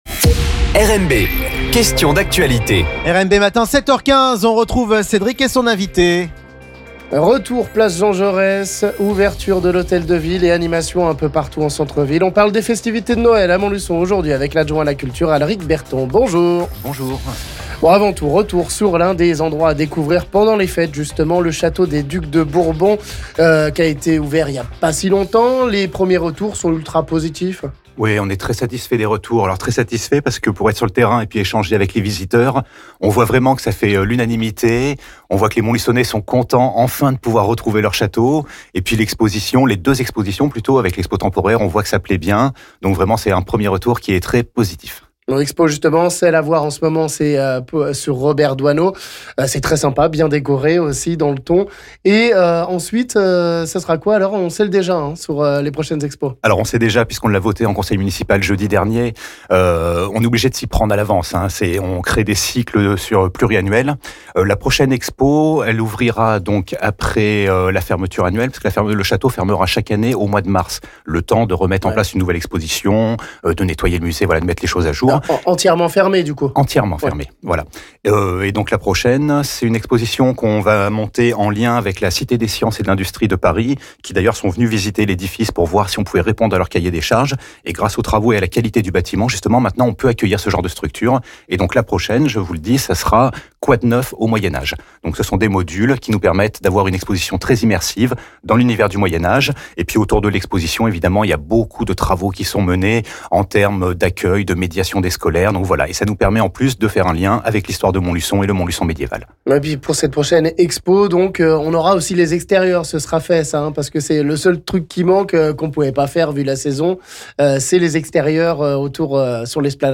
Un retour sur la Place Jean Jaurès avec patinoire, carrousel, chalets de Noël et nombreuses animations, des événements prévus dans l'Hôtel de ville qui va enfin rouvrir, des illuminations, mapping, et animations lumineuses...la ville de Montluçon va lancer dès ce vendredi 5 décembre ses festivités de Noël, et on détaille le programme avec l'adjoint à la culture Alric Berton...